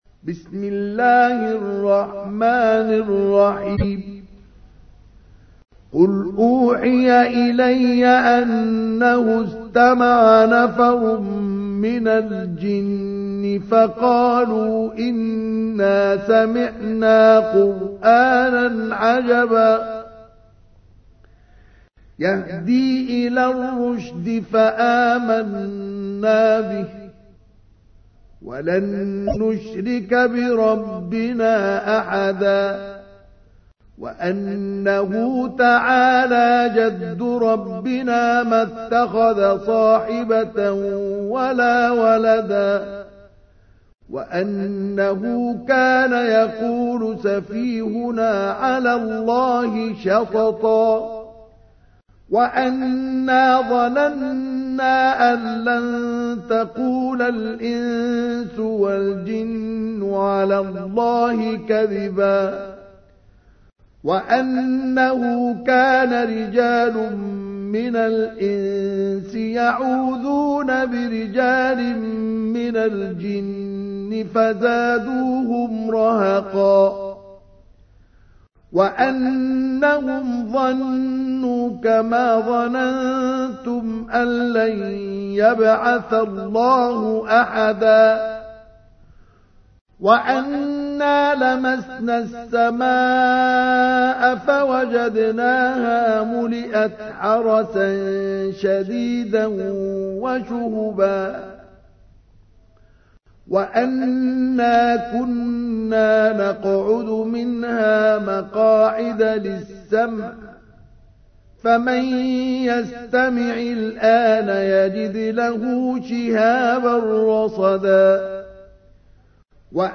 تحميل : 72. سورة الجن / القارئ مصطفى اسماعيل / القرآن الكريم / موقع يا حسين